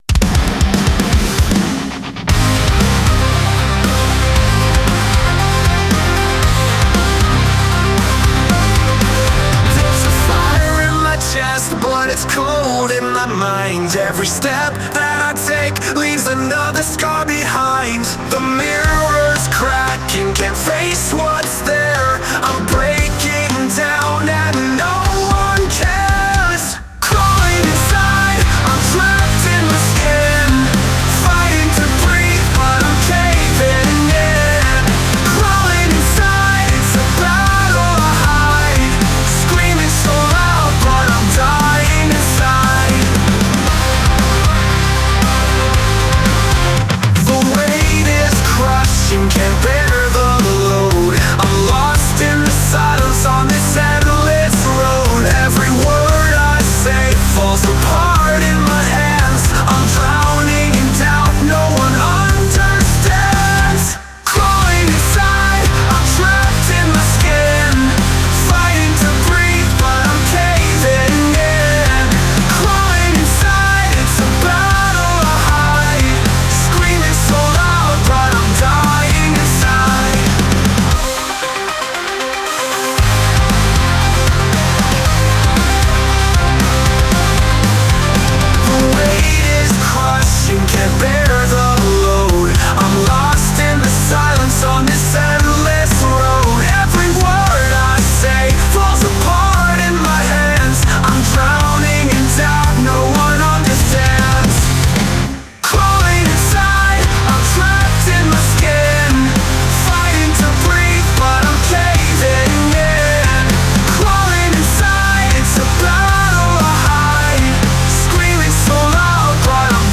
Genre: METAL